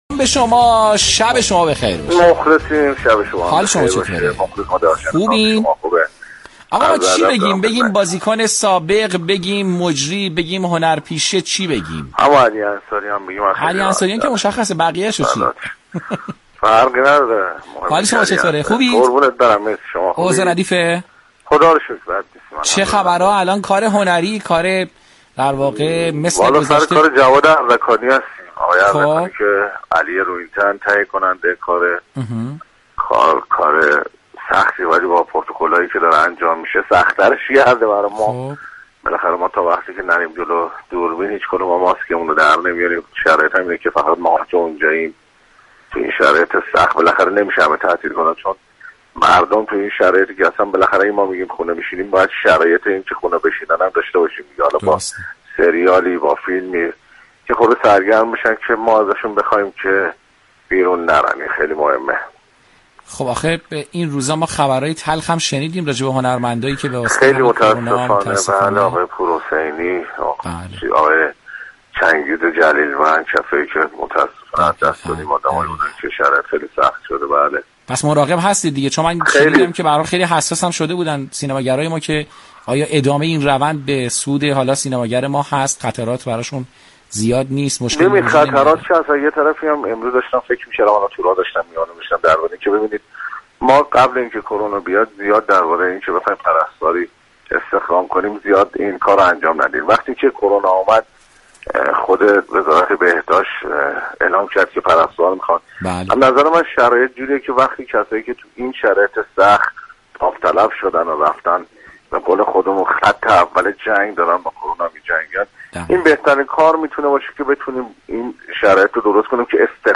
گفتگوی ماندگار رادیو ورزش با علی انصاریان
شبكه رادیویی ورزش گفتگویی شنیدنی را با این پیشكسوت محبوب انجام داده بود كه از طریق فایل پیوست می توانید آن را شنونده باشید.